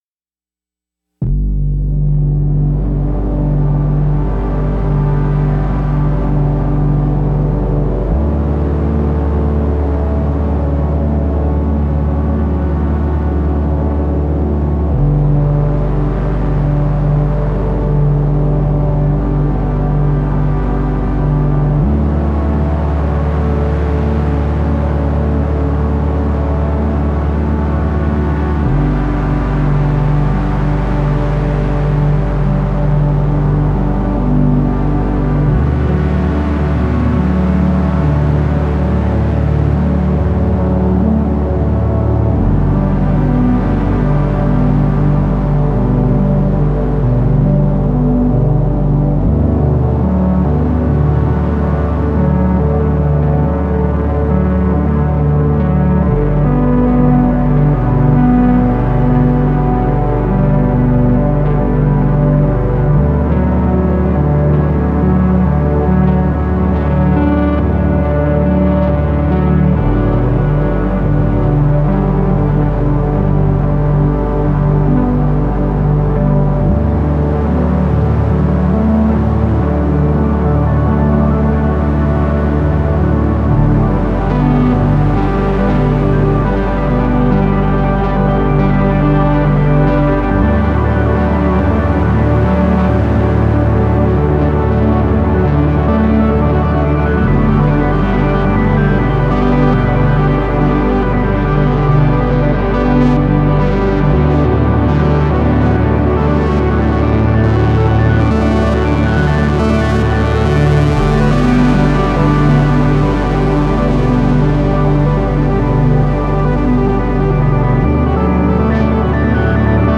Beatless Ambient with Rev2, PERfourMER, Sirin and miniKORG 700 FS > Boum
70 BPM